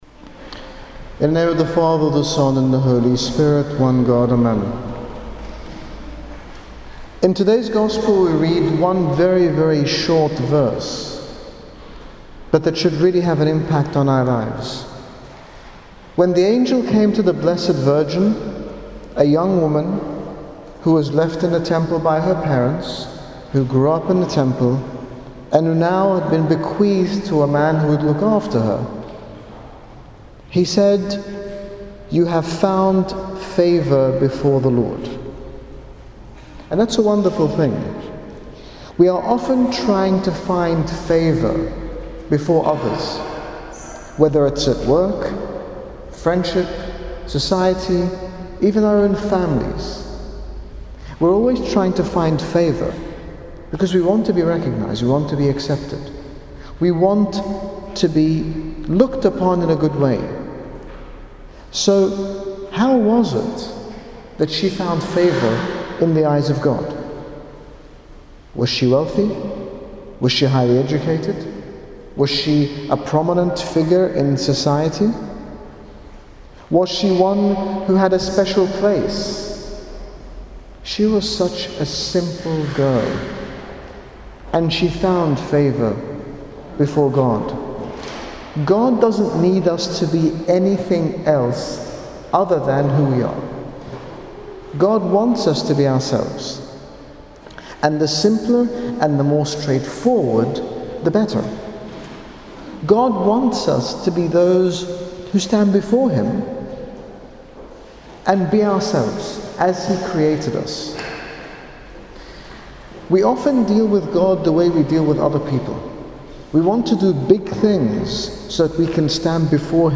His Grace Bishop Angaelos speaks about the struggle we all face in attempting to please one another while neglecting to be ourselves and seek favour in the eyes of God. When we only seek approval or acceptance from others it can often lead us to disappointment, uncertainty and confusion as their assessment of us can change.